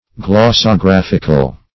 Glossographical \Glos`so*graph"ic*al\, a. Of or pertaining to glossography.
glossographical.mp3